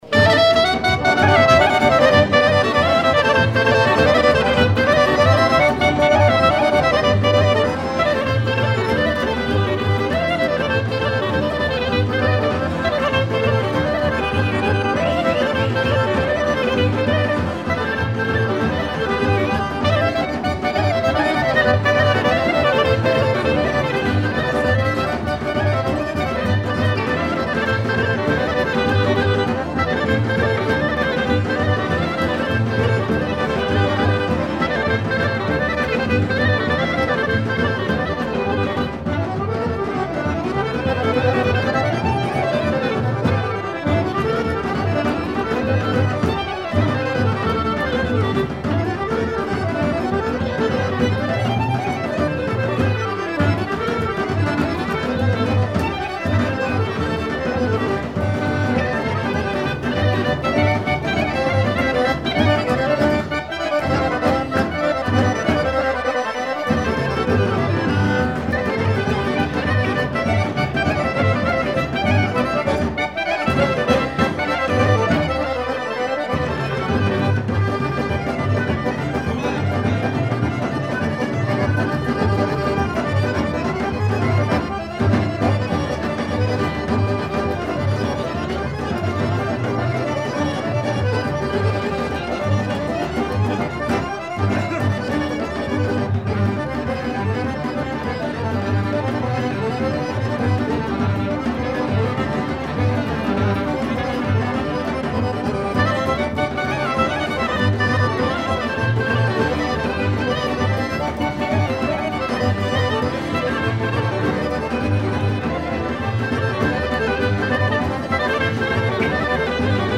Horo orchestre
Pièce musicale inédite